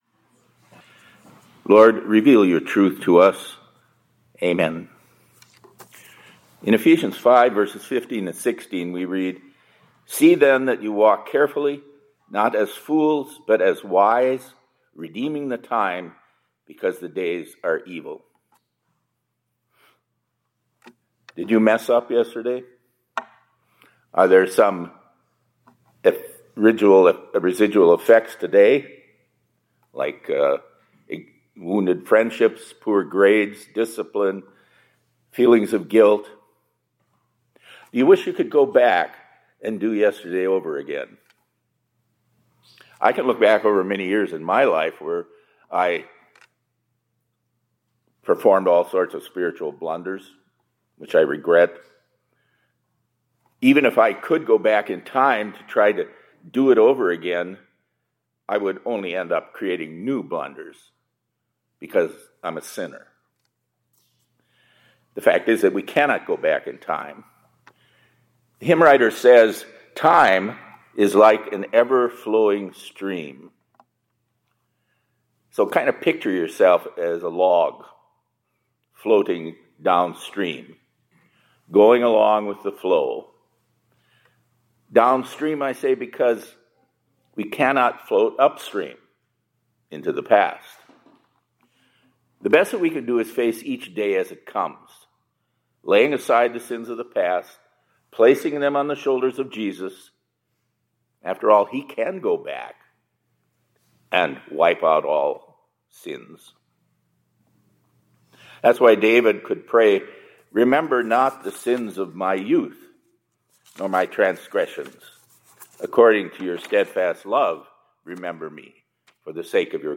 2026-02-24 ILC Chapel — Time is Like a Flowing Stream